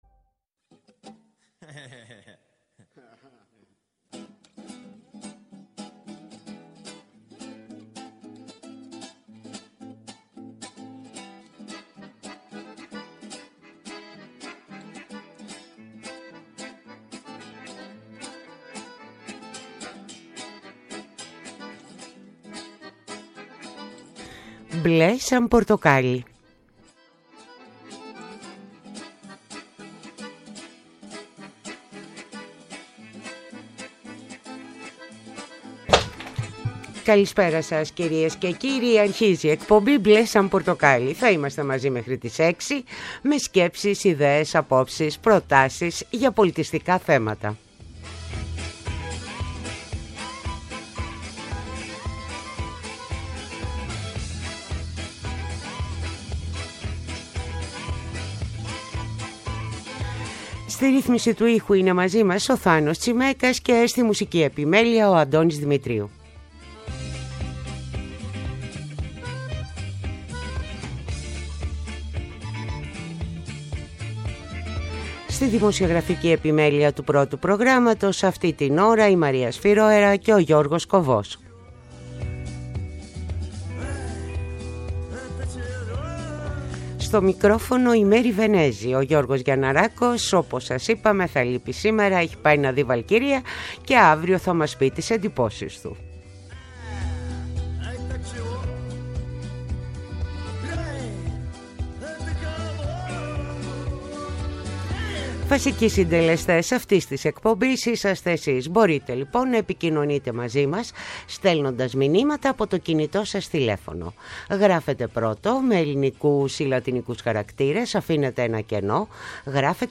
Τηλεφωνικά καλεσμένοι μας είναι οι: